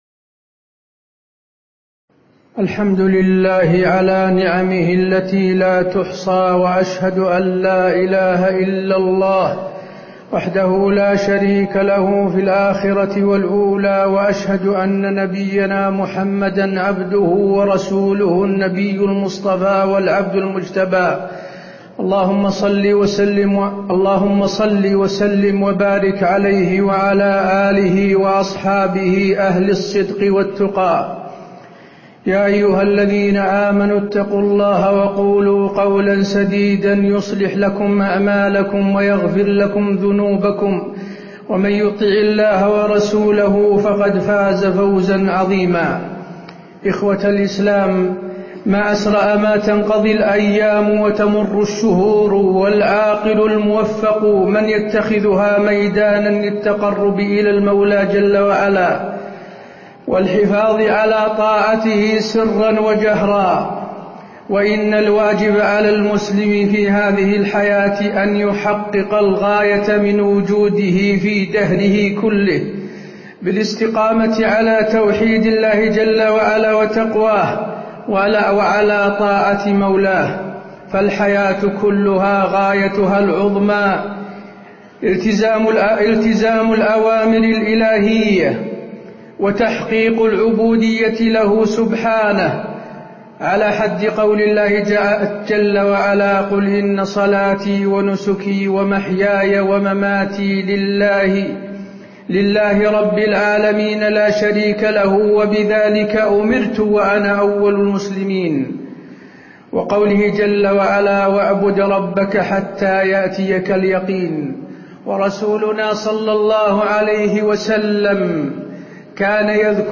تاريخ النشر ٣ شوال ١٤٣٧ هـ المكان: المسجد النبوي الشيخ: فضيلة الشيخ د. حسين بن عبدالعزيز آل الشيخ فضيلة الشيخ د. حسين بن عبدالعزيز آل الشيخ ماذا بعد شهر رمضان